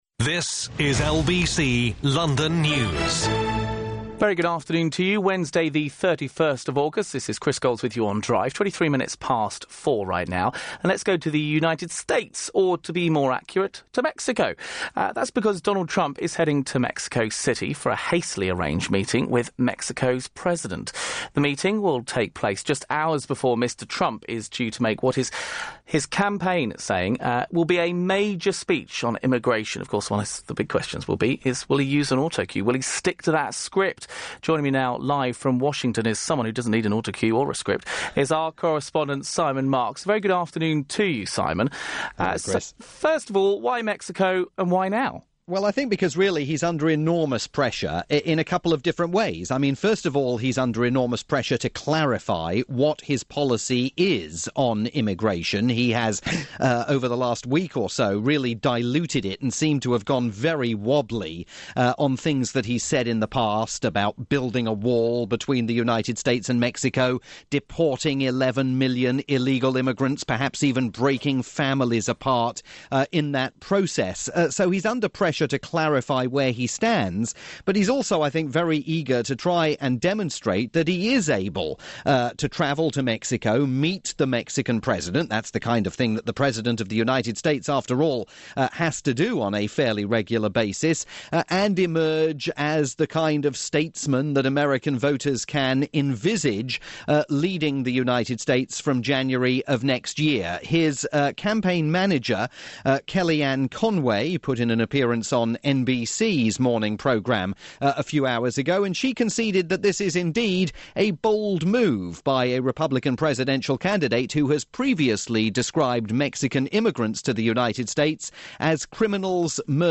on Britain's rolling news station LBC London News